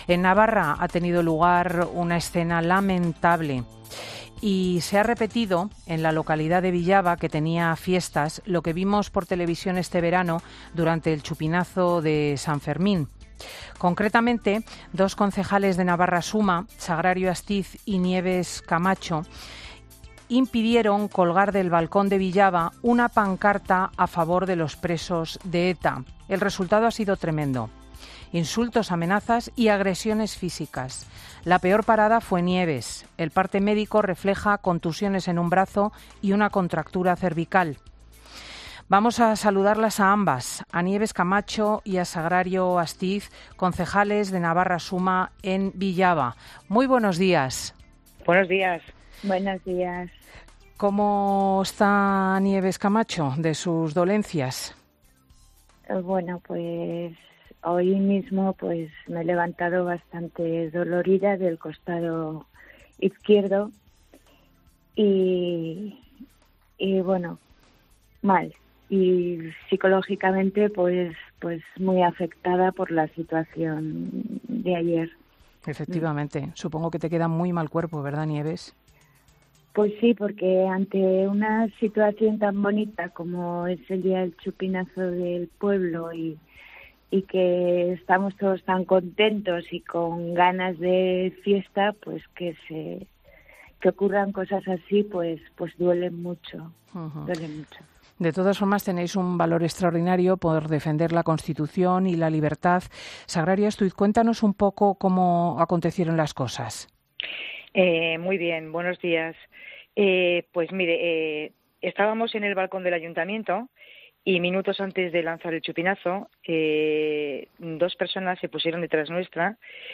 Nieves Camacho y Sagrario Astiz han asegurado en 'Fin de Semana' que, tras la agresión sufrida este sábado, “ningún partido” se ha interesado por su salud